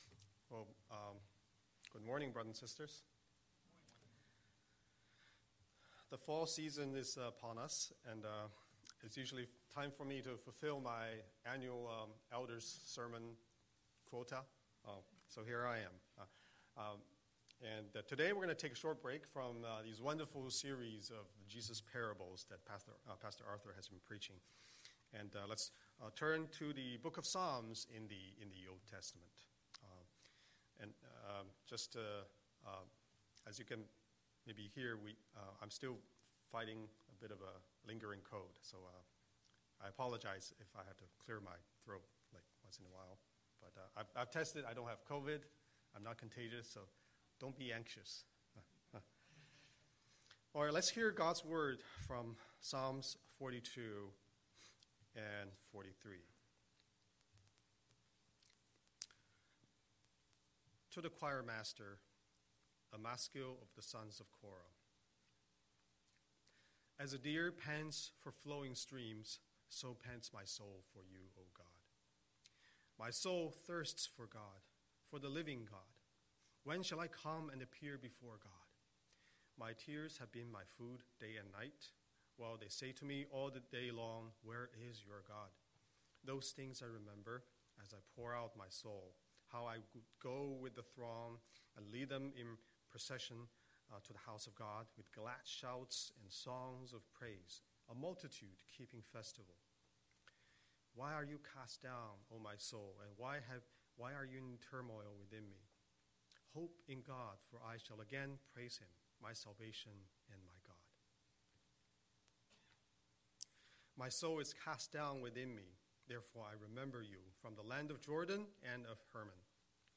Scripture: Psalm 42:1–43:5 Series: Sunday Sermon